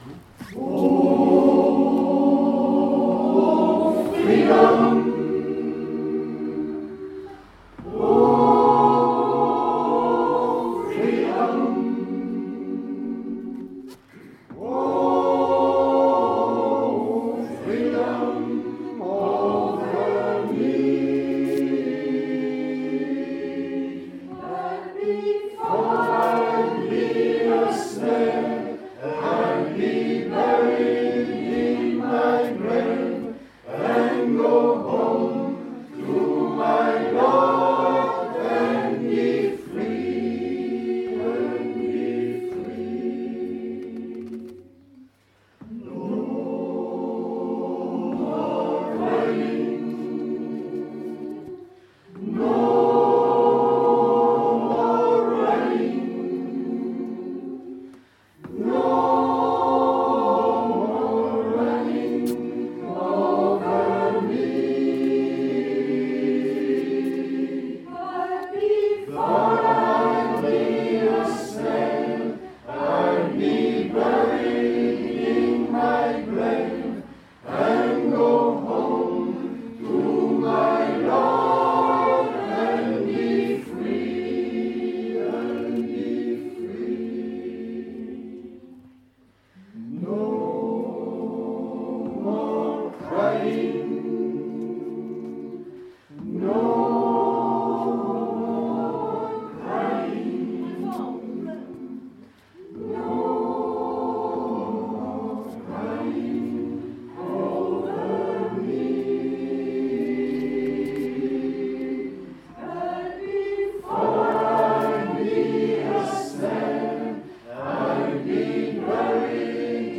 Oh Freedom, chant populaire afro-américain